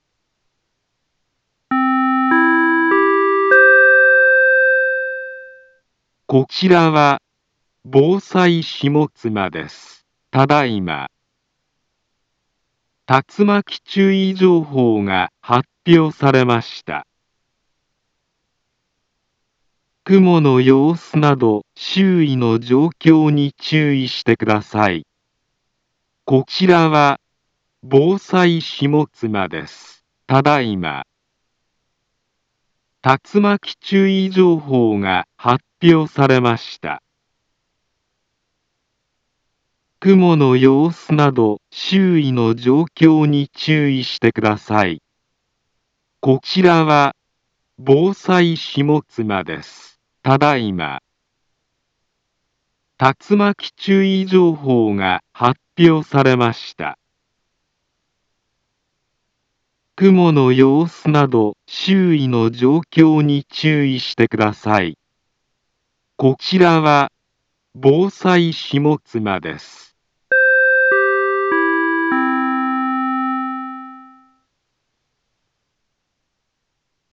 Back Home Ｊアラート情報 音声放送 再生 災害情報 カテゴリ：J-ALERT 登録日時：2021-08-23 09:15:06 インフォメーション：茨城県南部は、竜巻などの激しい突風が発生しやすい気象状況になっています。